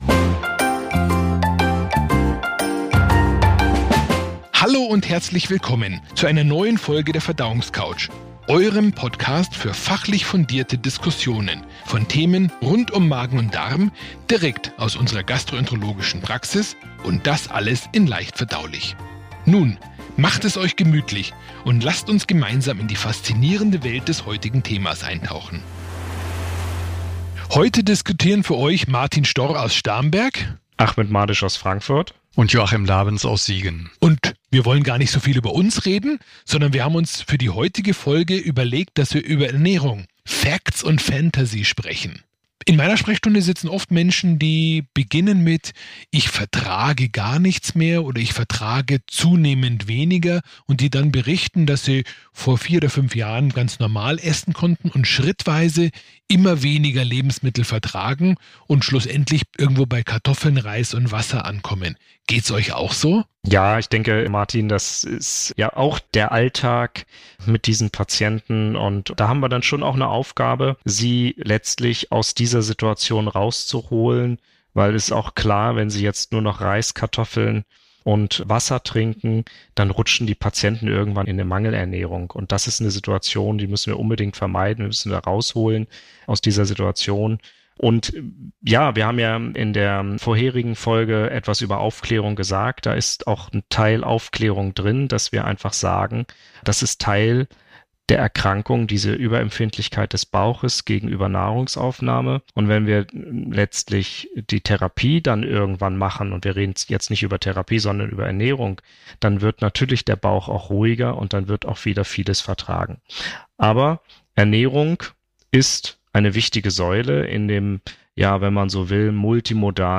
Gluten, Laktose, Fruktose und Co - Sinn und Unsinn von Ernährung und Ernährungstherapie bei chronischen Beschwerden des Verdauungstrakts. Drei Experten verraten Ihnen, wie sie mit diesem komplexen Problem umgehen.